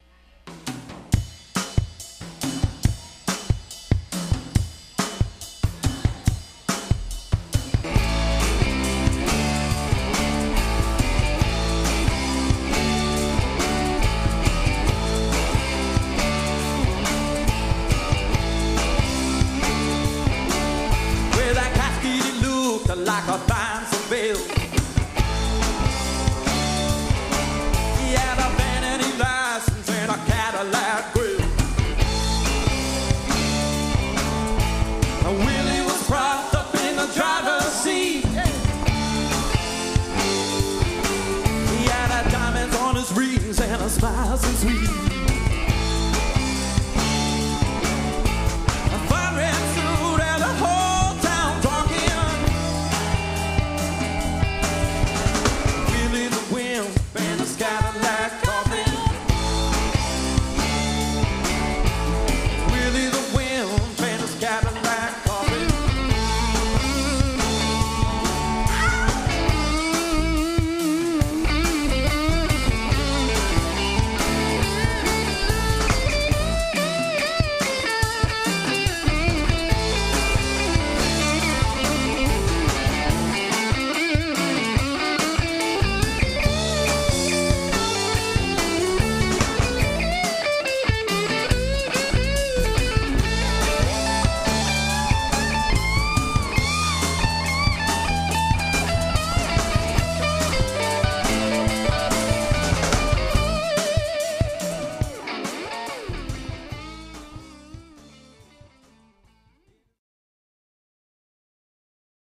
Sound clips of the band